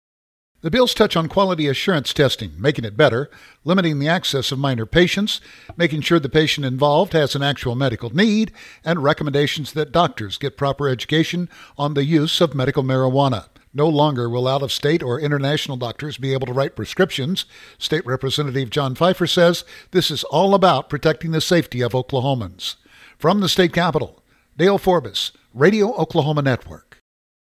provides the details.